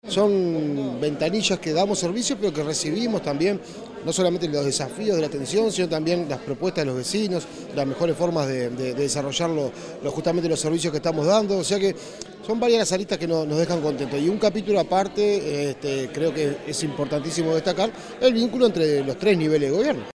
El Gobierno de Canelones y el Correo Uruguayo realizaron la inauguración del Centro de Cercanía de 18 de Mayo, ubicado en la calle Av. Maestro Julio Castro esquina Solís.